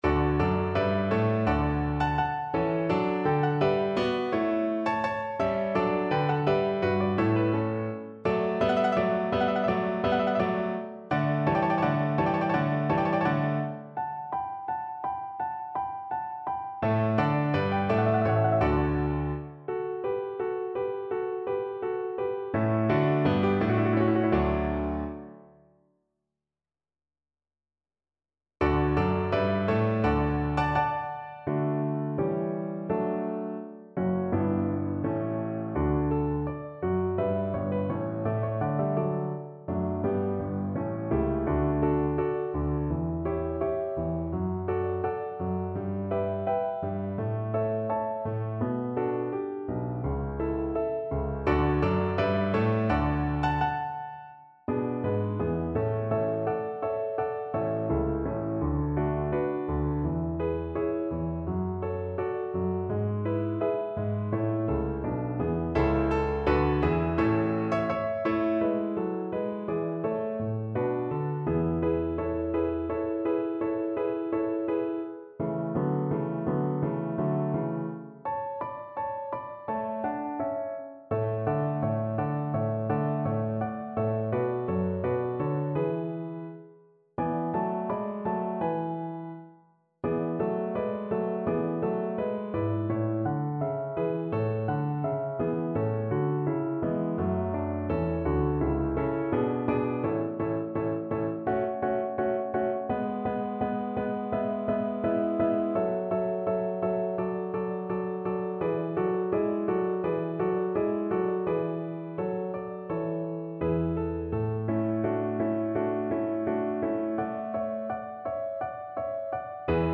Free Sheet music for Cello
Cello
D major (Sounding Pitch) (View more D major Music for Cello )
Andante (=c.84)
Classical (View more Classical Cello Music)